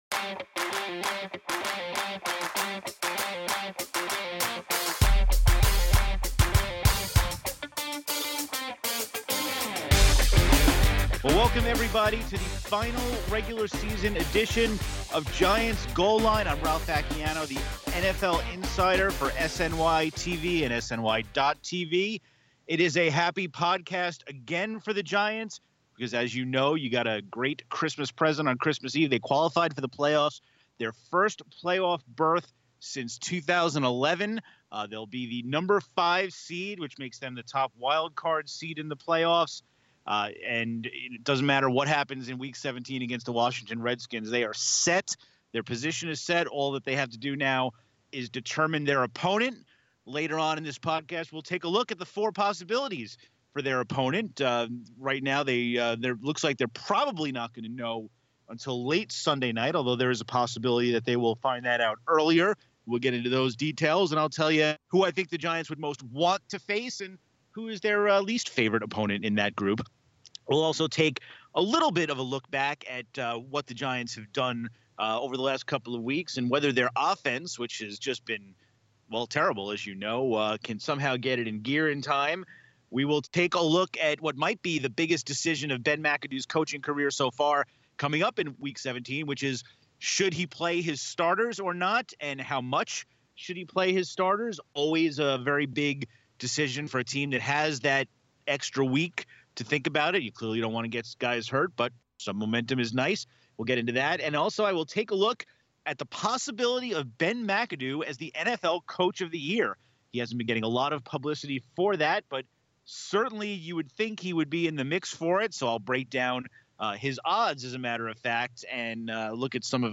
Later, Giants running back Rashad Jennings joins the show to talk about making the playoffs for the first time in his career, and also Big Blue’s preparation for Week 17 and beyond.